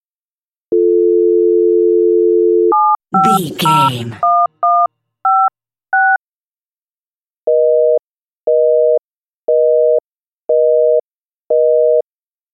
Telephone tone dial 7 numbers busy
Sound Effects
phone